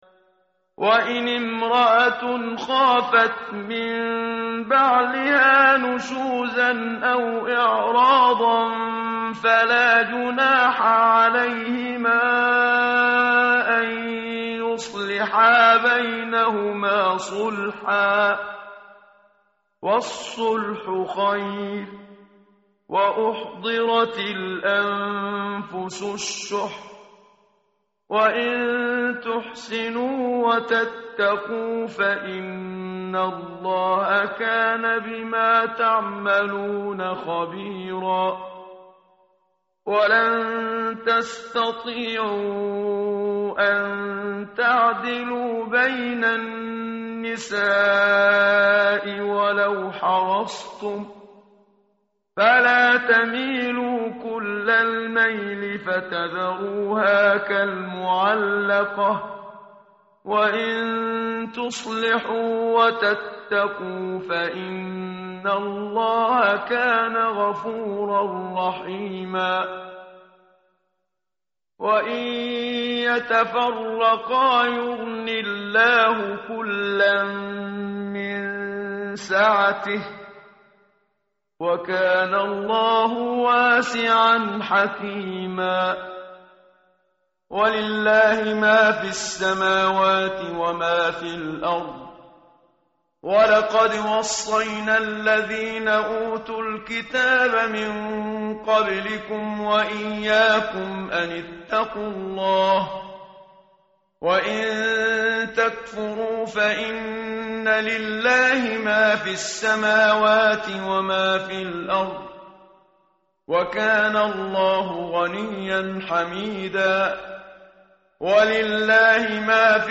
متن قرآن همراه باتلاوت قرآن و ترجمه
tartil_menshavi_page_099.mp3